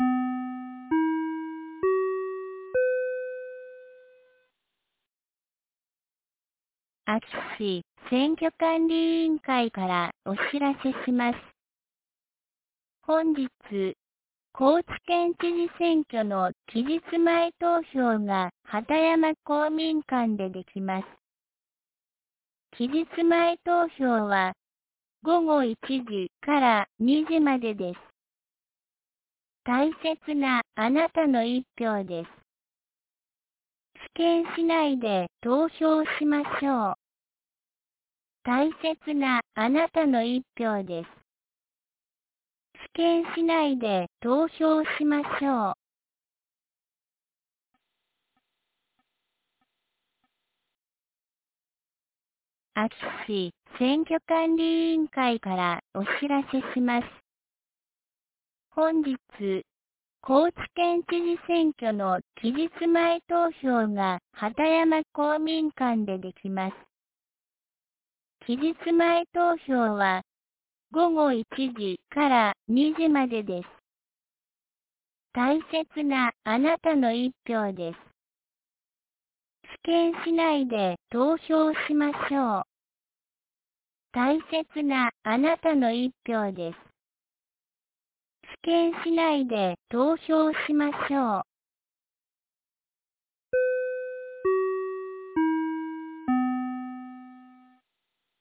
2023年11月22日 12時11分に、安芸市より畑山へ放送がありました。